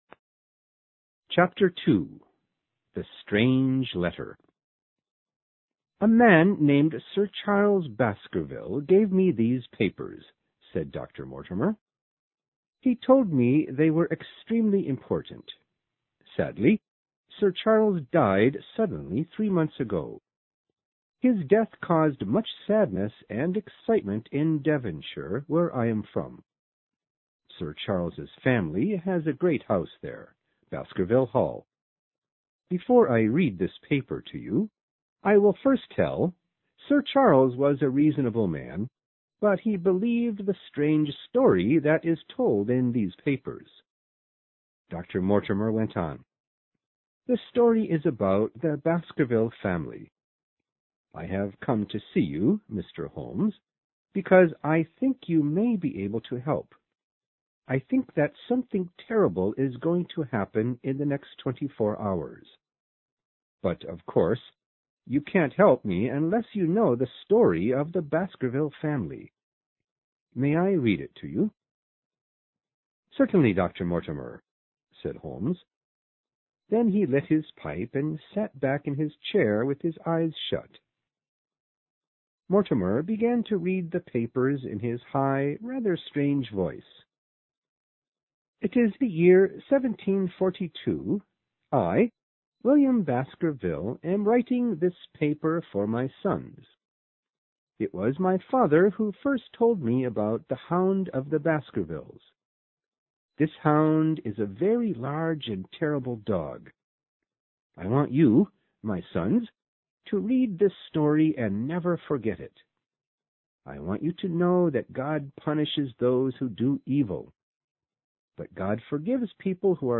有声名著之巴斯克维尔猎犬chapter2 听力文件下载—在线英语听力室